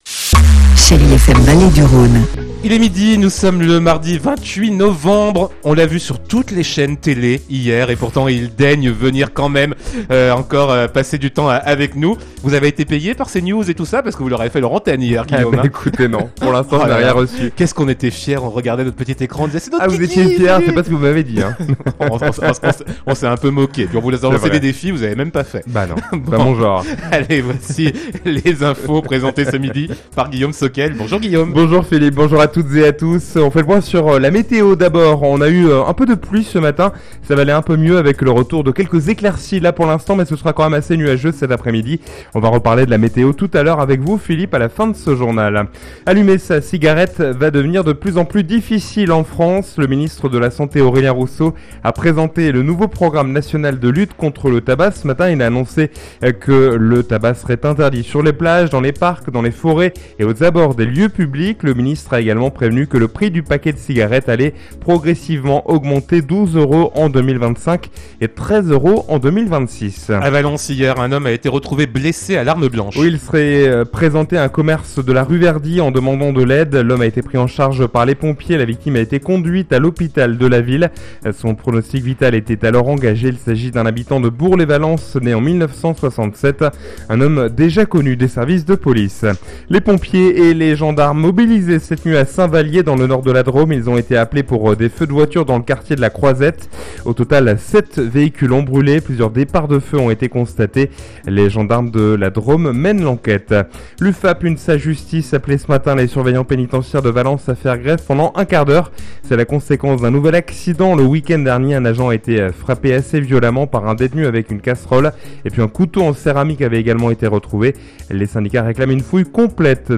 Mardi 28 novembre : Le journal de 12h